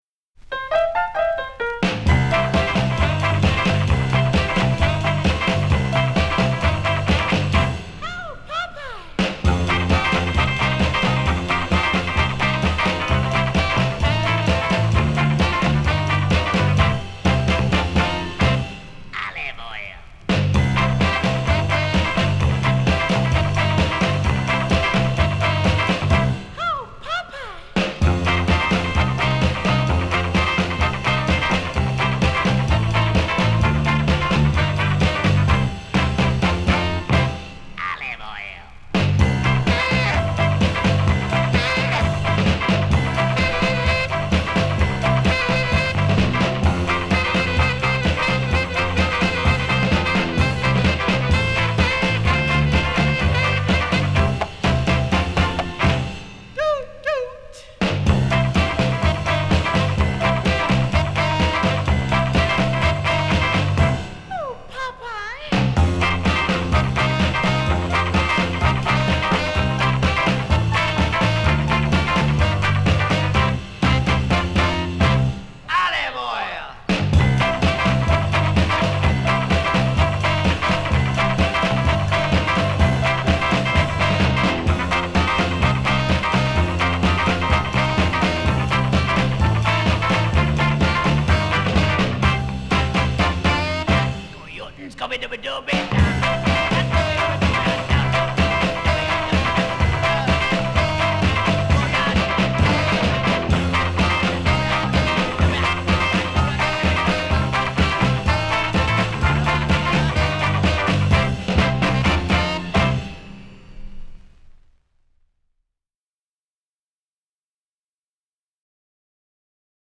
live LP
mostly instrumental tracks with a Southern California flair